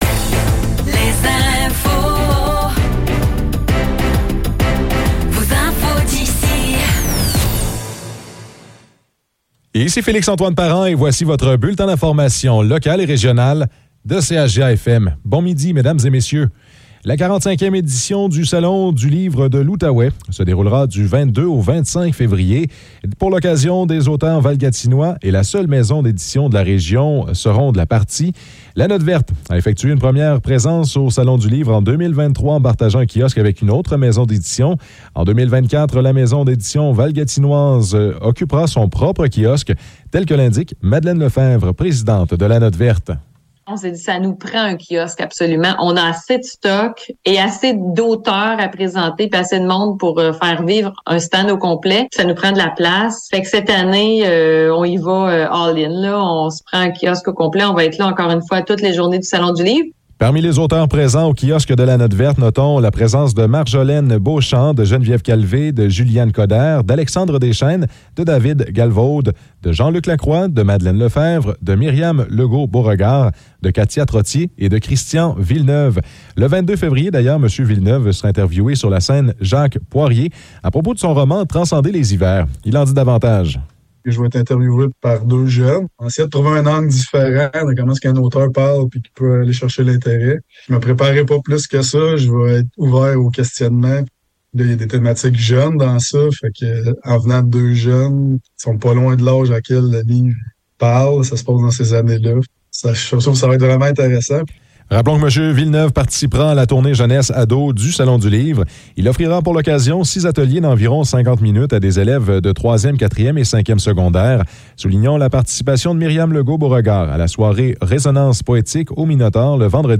Nouvelles locales - 5 février 2024 - 12 h